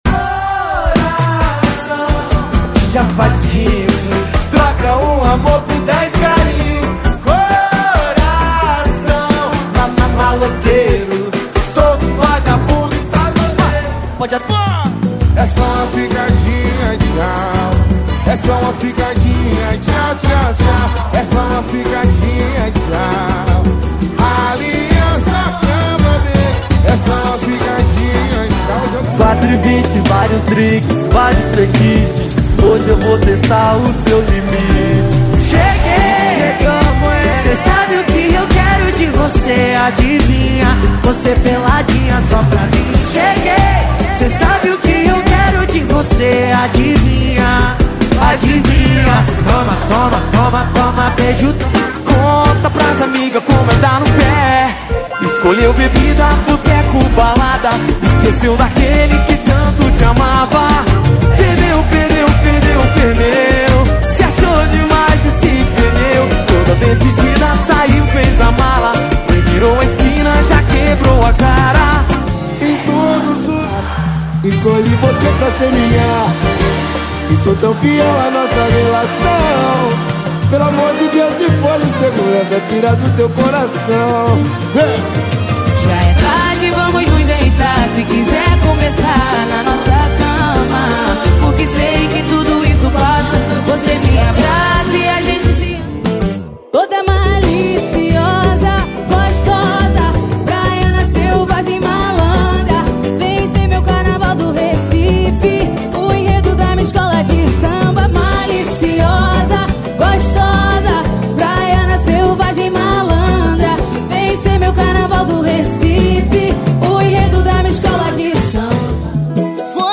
• Pagode e Samba = 50 Músicas
• Sem Vinhetas